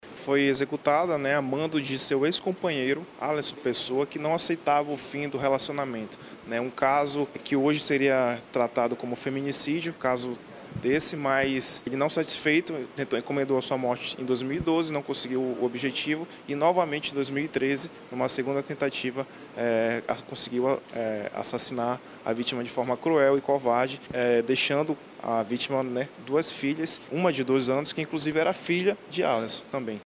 SONORA02_DELEGADO-.mp3